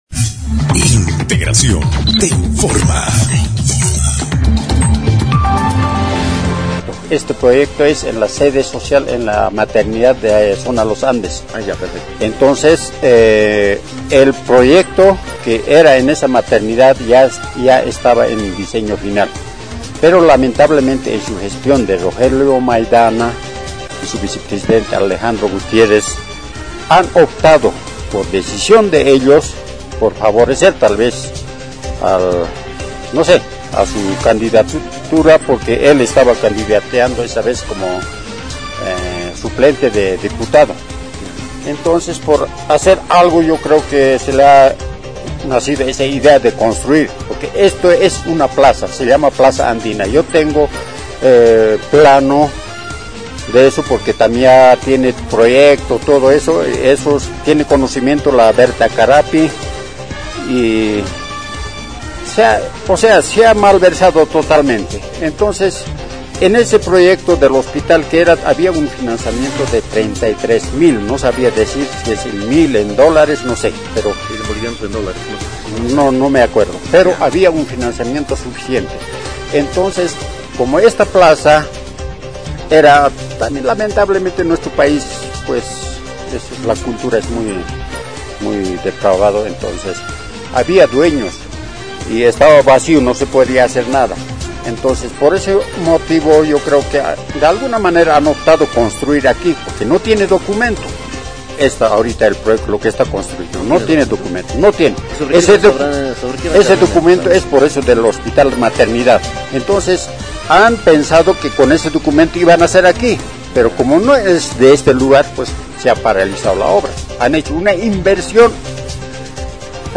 Testimonio de vecinos.
3-los-andes-testimonio.mp3